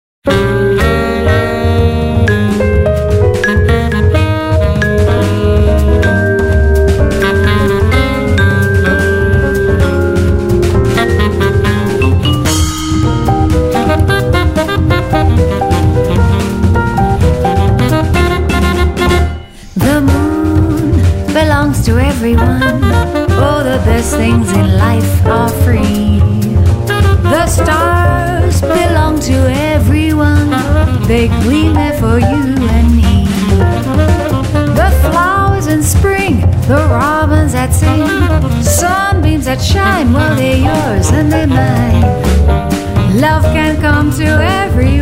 vocal&bass
vibraphone
piano
Recorded at Avatar Studio in New York on March 23 & 24, 2011